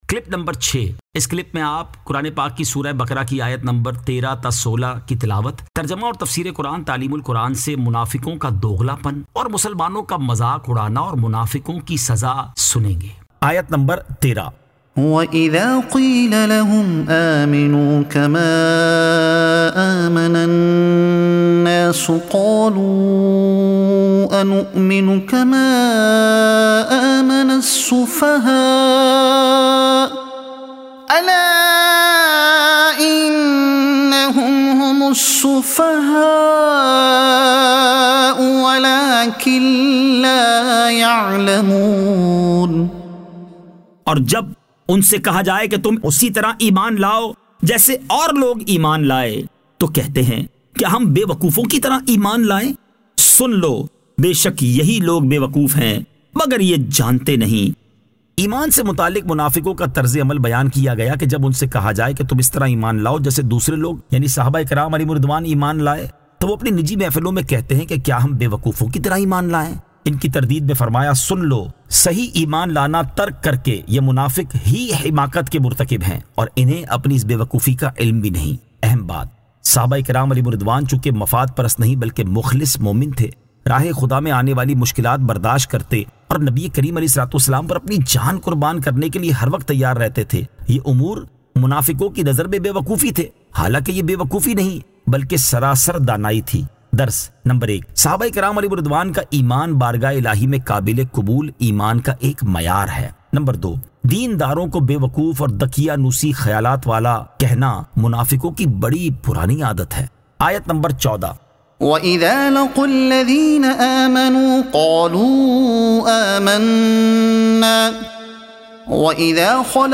Surah Al-Baqara Ayat 13 To 16 Tilawat , Tarjuma , Tafseer e Taleem ul Quran